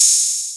Open Hat [ Trak ].wav